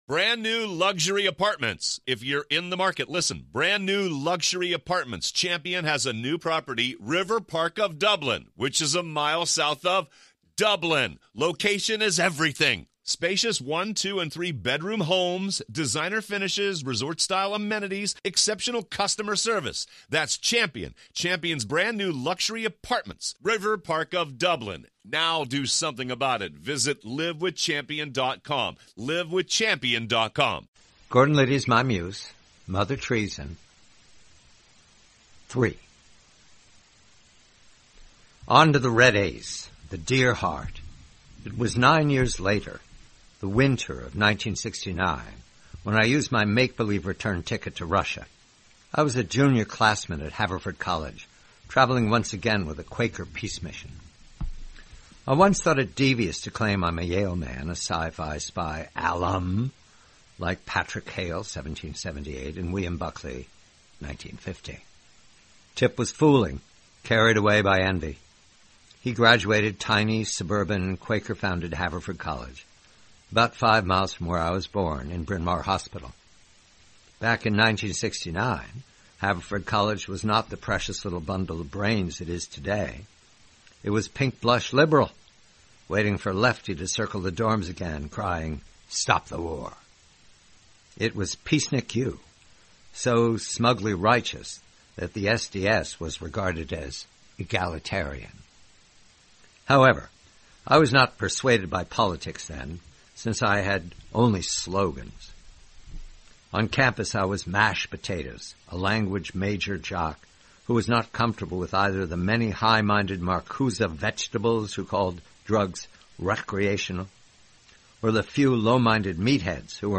Read by John Batchelor.